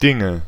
Ääntäminen
Synonyymit Kram Ääntäminen Tuntematon aksentti: IPA: /ˈdɪŋə/ IPA: /diŋø/ Haettu sana löytyi näillä lähdekielillä: saksa Käännöksiä ei löytynyt valitulle kohdekielelle. Dinge on sanan Ding monikko.